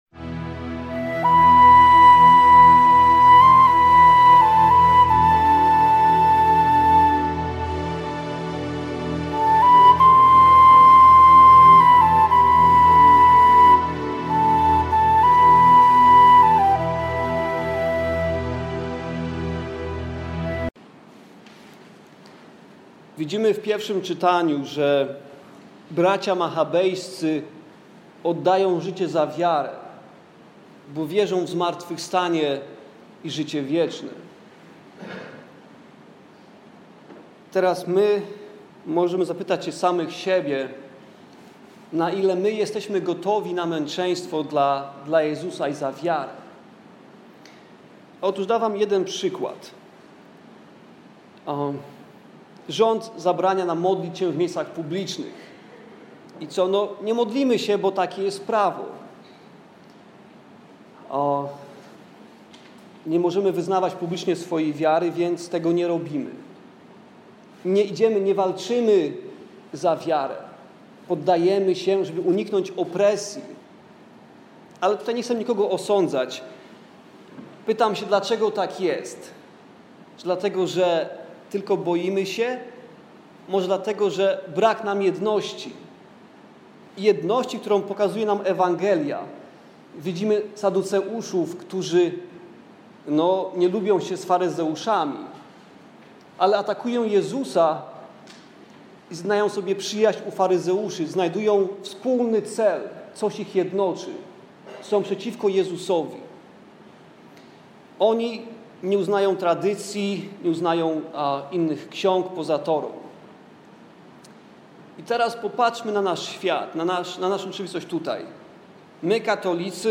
homilia o jednosci w srod chrzescijan. 32 niedziela
faryzeusze i saduceusze jednocza sie przeciwko Jezusowi. potrafia przejsc ponad ogromnymi roznicami.a my jako Polacy?                                                                         homilia wygloszona dla Polonii w Birmingham 9.11.2013